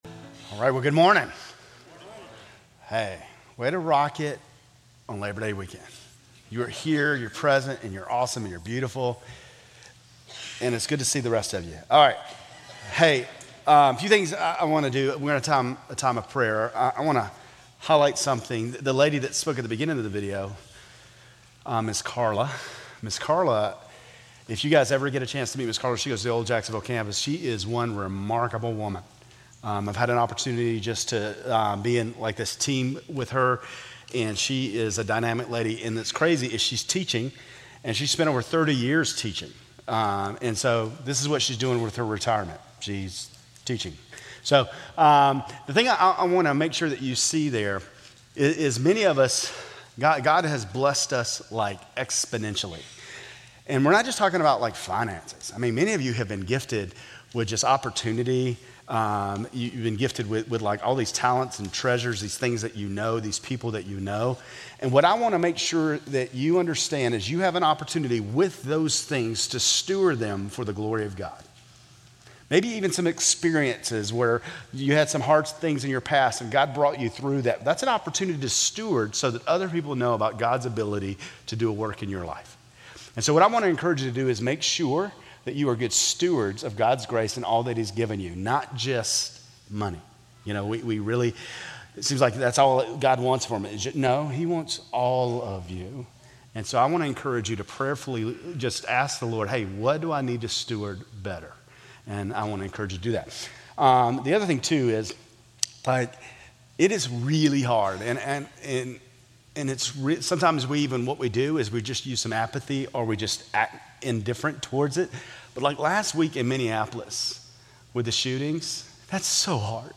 Grace Community Church Lindale Campus Sermons 8_31 Lindale Campus Aug 31 2025 | 00:31:43 1x 00:00 / 00:31:43 Subscribe Share RSS Feed Share Link Embed